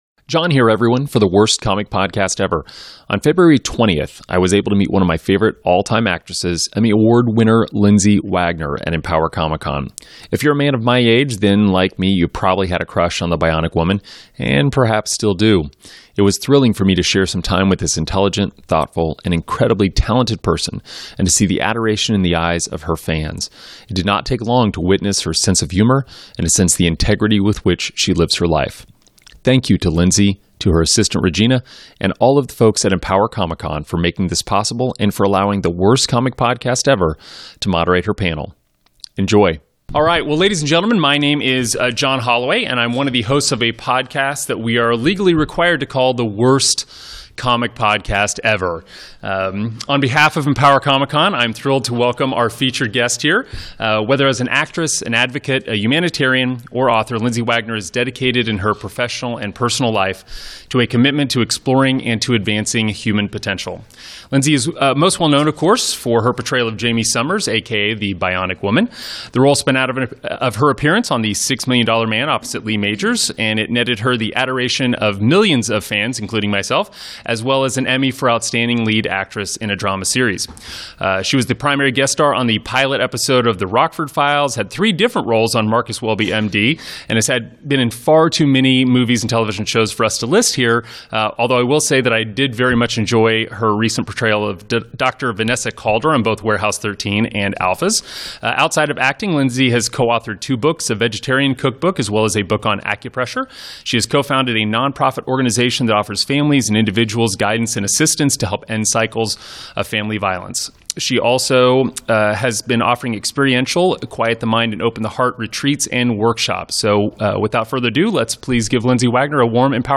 Lindsay Wagner Panel from Empower Comic Con
lindsay-wagner-interview.m4a